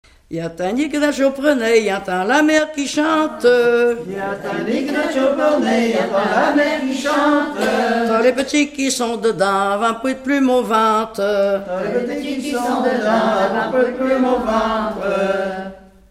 Mémoires et Patrimoines vivants - RaddO est une base de données d'archives iconographiques et sonores.
danse : branle : courante, maraîchine
Pièce musicale inédite